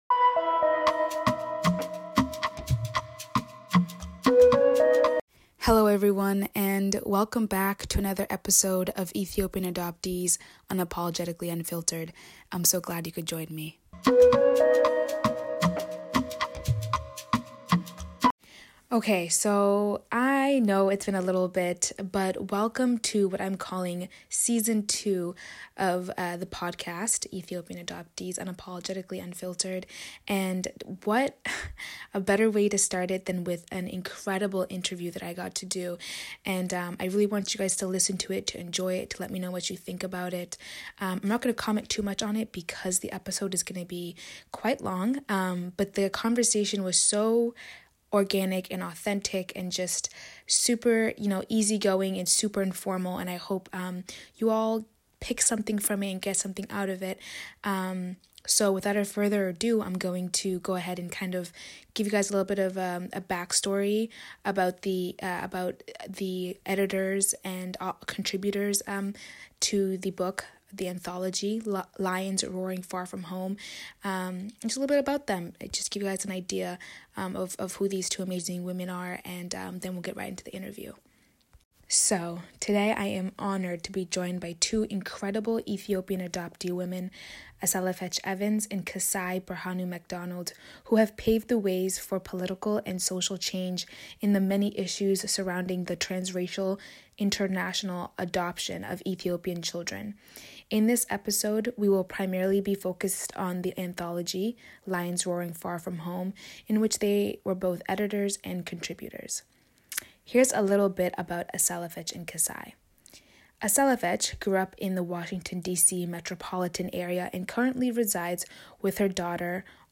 The conversation is organic, honest, and adoptee-centered.